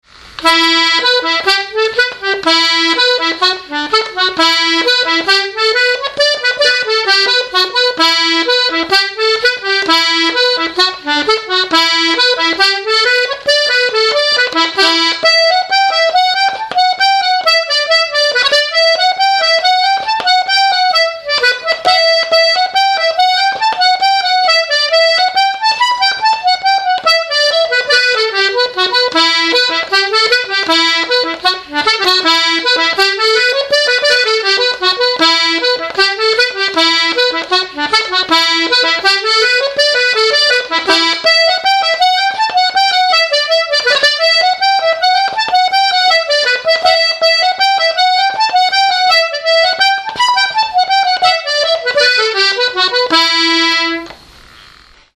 Man of the House (Reel)
This traditional reel is a popular accordion piece, re-popularized in modern times by Galway accordion player Joe Burke, so it should be no surprise that it is our local box player who offers it up.